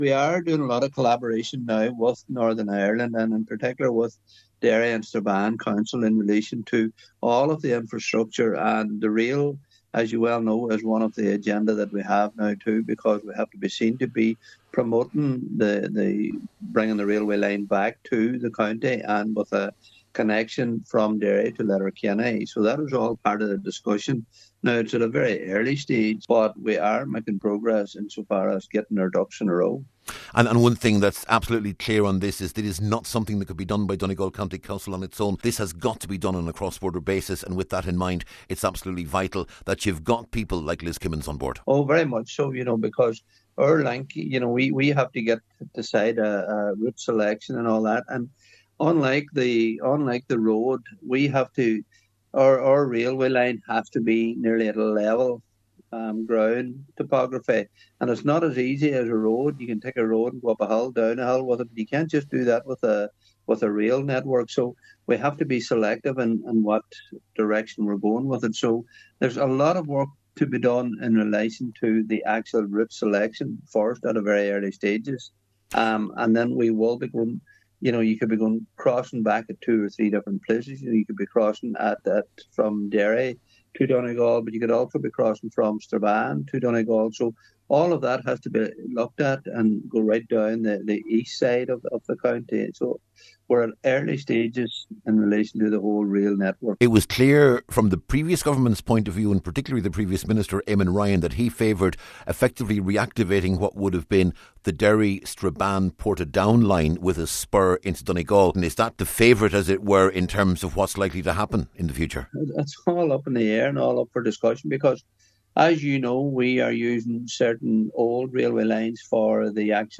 Cllr Paul Canning says planning is at a very early stage………